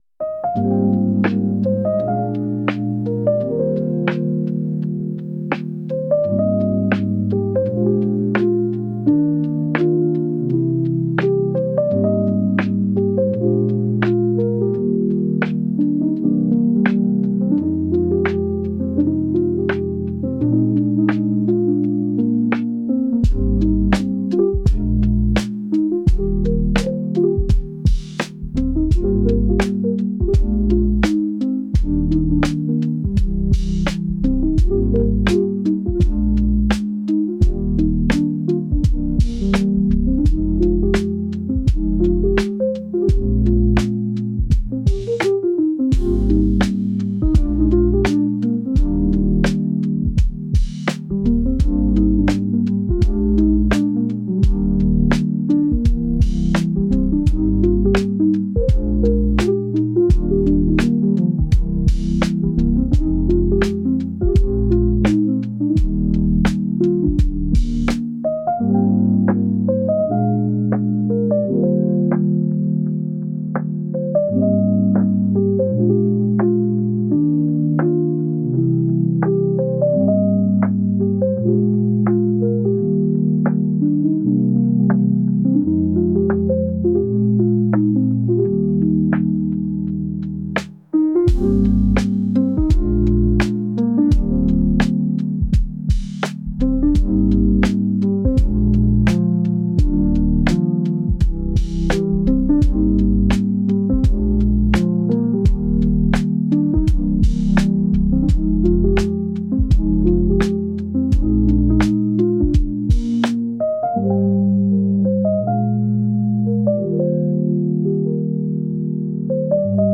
ジャンルJAZZ
楽曲イメージBar, Chill, Lo-Fi, ゆったり, カフェ, ムーディー, , 大人, 日常
ピアノの影が静かに伸び、遠い記憶を手繰り寄せる。
静かな調べが、日常の疲れをそっと癒していく。